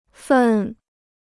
份 (fèn) Free Chinese Dictionary